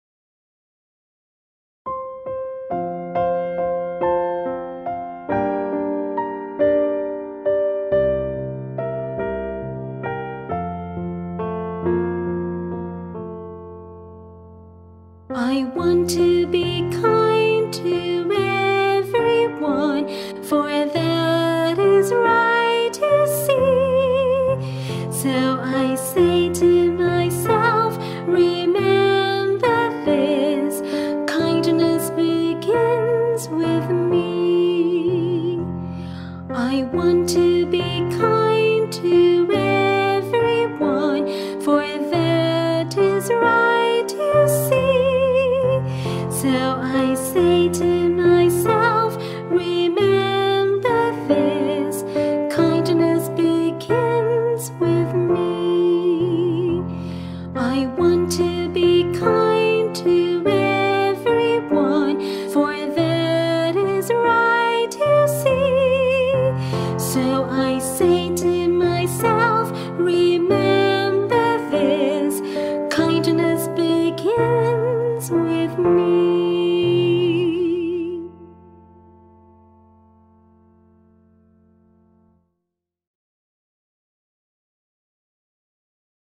Music & Vocals Video Bullying